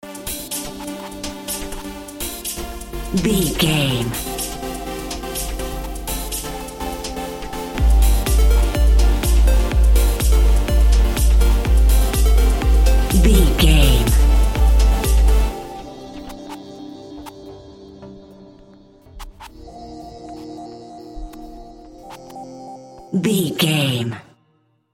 Aeolian/Minor
groovy
uplifting
driving
energetic
repetitive
synthesiser
drum machine
house
instrumentals
synth bass
uptempo